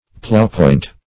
Search Result for " ploughpoint" : The Collaborative International Dictionary of English v.0.48: Plowpoint \Plow"point`\, Ploughpoint \Plough"point`\, n. A detachable share at the extreme front end of the plow body.